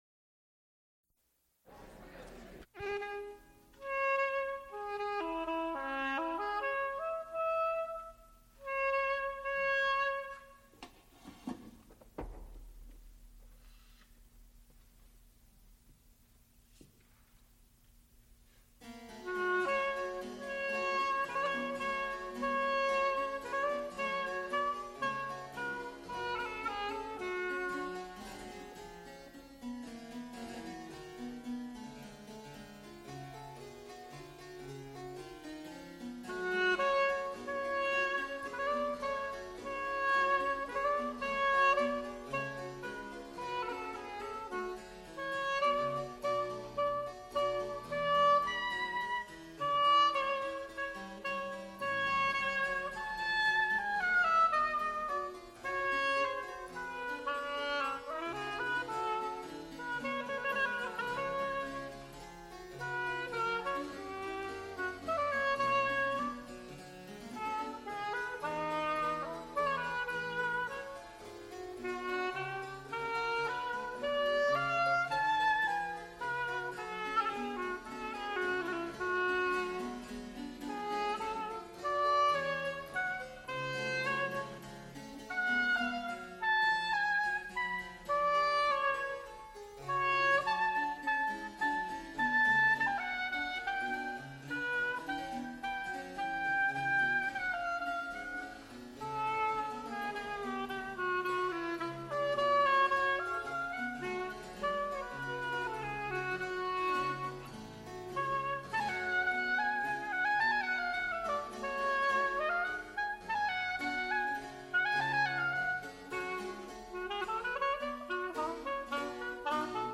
oboe, recorder
Recorded live February 16, 1978, Frick Fine Arts Auditorium, University of Pittsburgh.
Extent 3 audiotape reels : analog, quarter track, 7 1/2 ips ; 7 in.
Sacred songs (High voice) with continuo